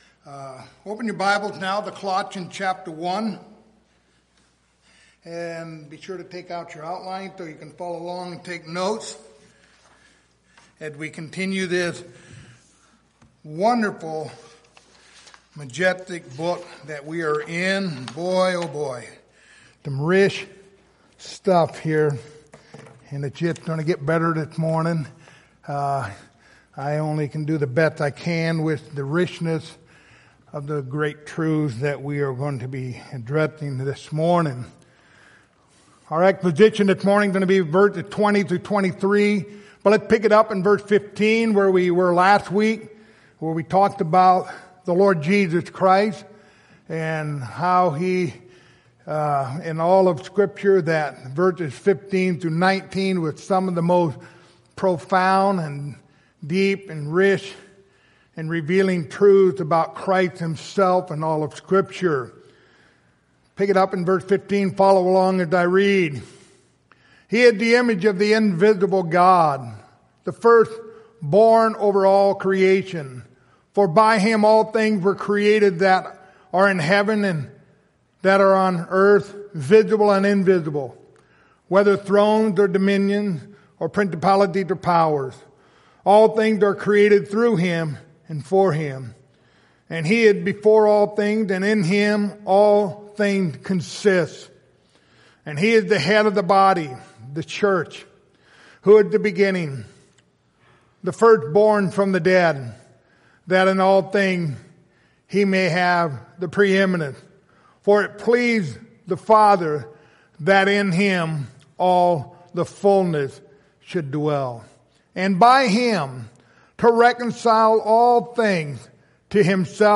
Passage: Colossians 1:20-23 Service Type: Sunday Morning